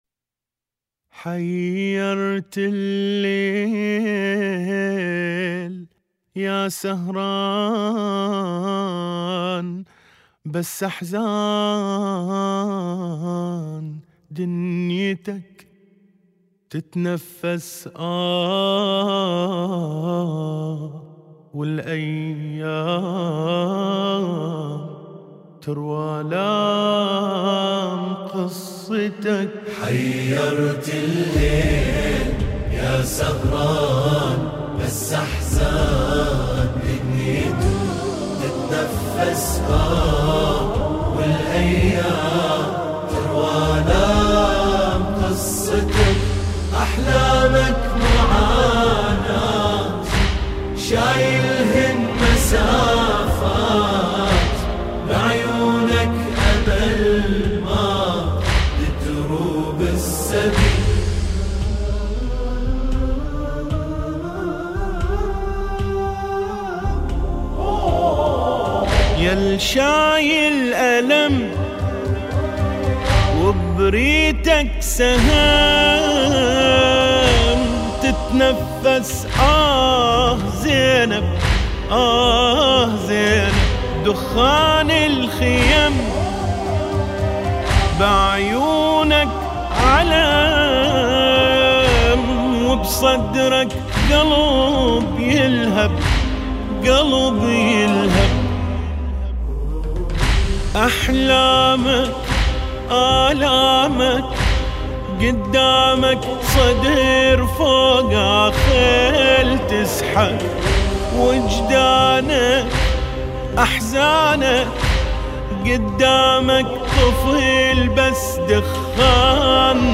لطميات شهر محرم